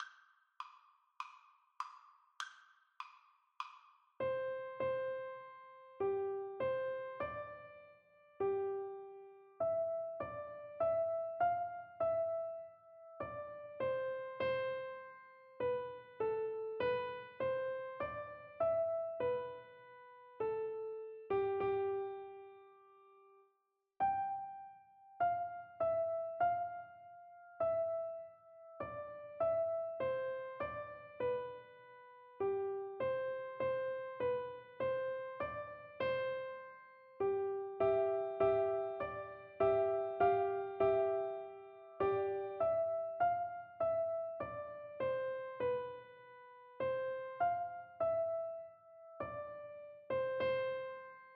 Christmas Christmas Piano Four Hands (Piano Duet) Sheet Music O Come, All Ye Faithful
Free Sheet music for Piano Four Hands (Piano Duet)
4/4 (View more 4/4 Music)
C major (Sounding Pitch) (View more C major Music for Piano Duet )
Piano Duet  (View more Easy Piano Duet Music)
Traditional (View more Traditional Piano Duet Music)